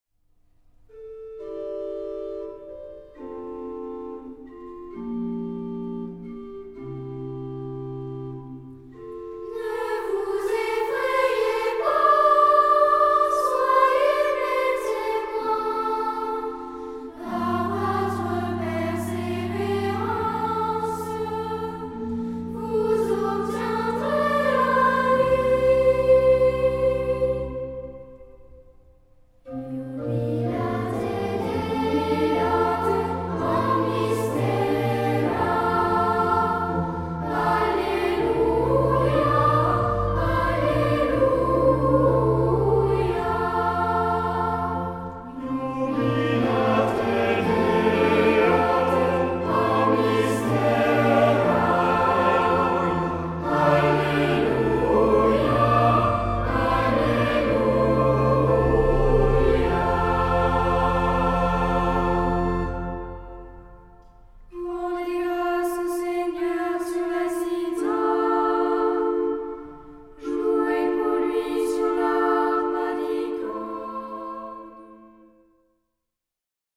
Genre-Stil-Form: Tropar ; Psalmodie
Charakter des Stückes: andächtig
Instrumente: Orgel (1) ; Melodieinstrument (ad lib)
Tonart(en): F-Dur